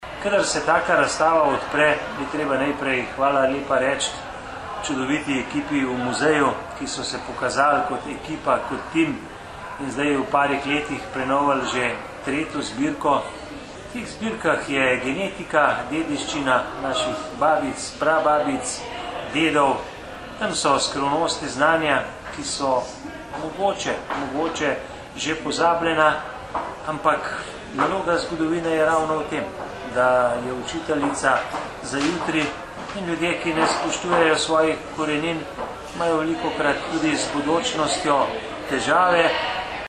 95091_izjava_mag.borutsajoviczupanobcinetrziconovistalnirazstavi.mp3